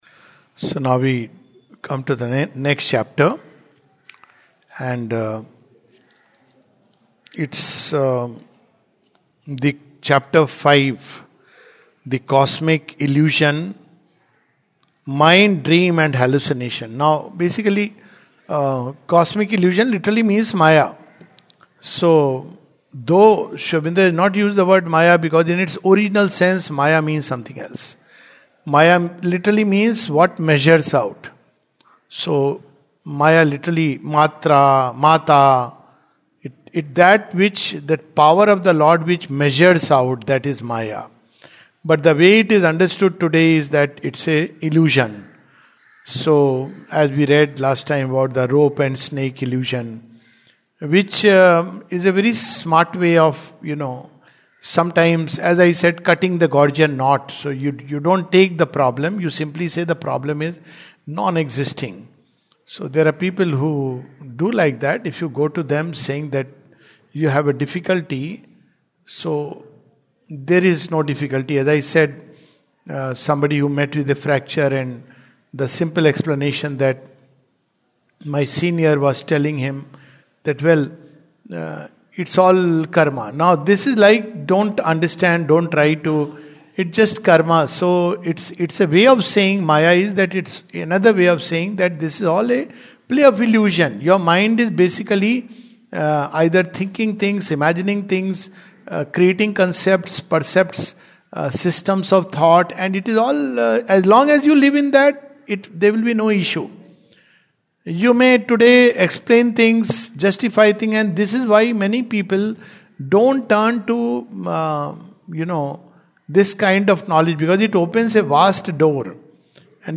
The Life Divine, 26th February 2026, Session # 06-09 at Sri Aurobindo Society, Pondicherry - 605002, India. This is the summary of a portion of Chapter 4 of Book Two of The Life Divine.